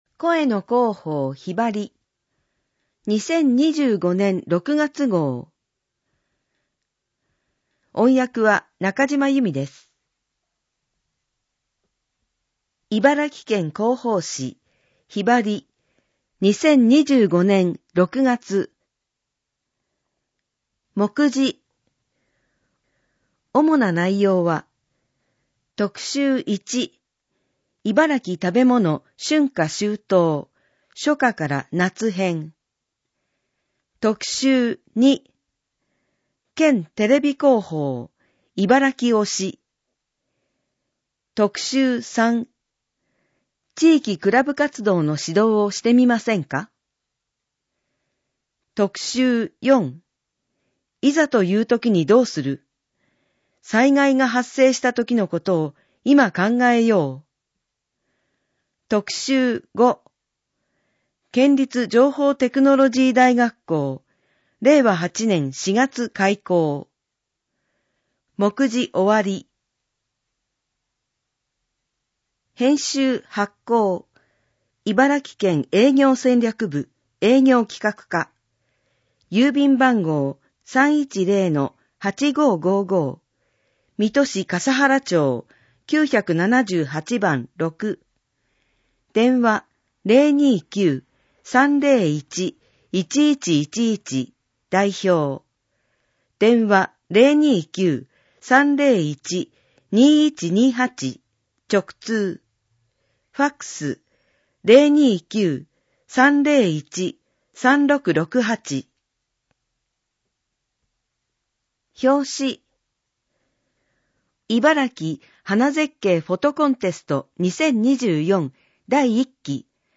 【特集4】いざというときにどうする？～災害が発生したときのことを、いま考えよう～ 【特集5】県立情報テクノロジー大学校 令和8年4月開校! 【お知らせひろば】 県からのお知らせ 【催し物ガイド】 県内の美術館・博物館などの企画展をご紹介 【その他トピックス】知事コラム、クロスワードパズルなど 知事コラム、クロスワードパズルなど 音声版・点字版・電子版県広報紙「ひばり」 音声版 視覚障害の方を対象に音声版も発行しています。 声の広報「ひばり」 音声を再生するためには、 「QuicktimePlayer」（外部サイトへリンク） 、 「WindowsMediaPlayer」（外部サイトへリンク） 、 「RealPlayer」（外部サイトへリンク） （いずれも無料）などが必要です。